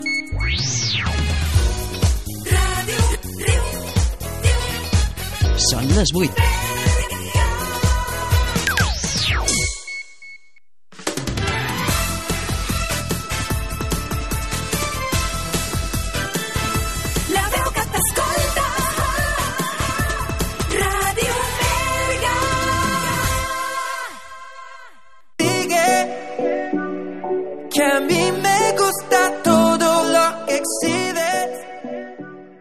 Hora, indicatiu de l'emissora, indicatiu "la veu que t'escolta", tema musical.